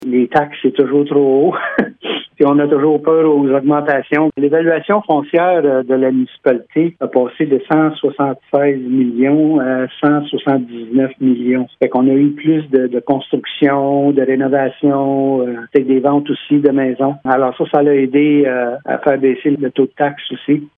Le maire de Denholm, Pierre-Nelson Renaud, se réjouit de cette nouvelle qu’il explique par l’augmentation de l’activité immobilière sur le territoire de la Municipalité :